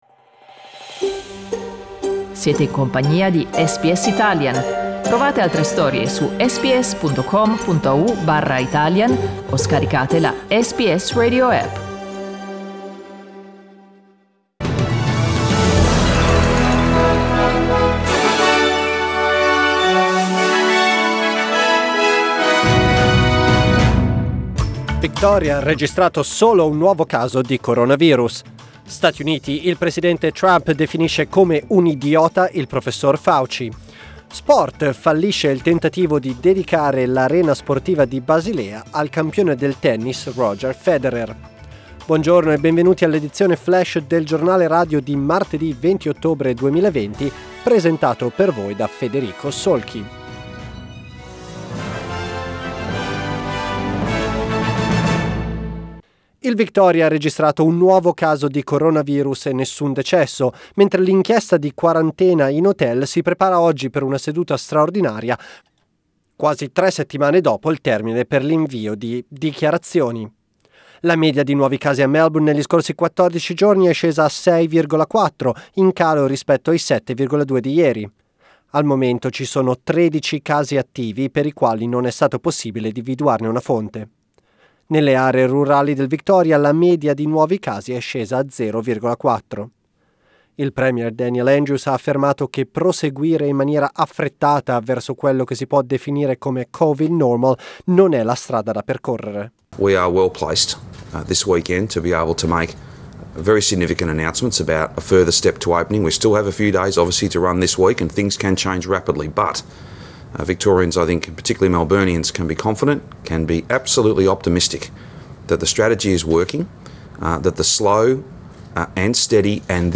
Our news update in Italian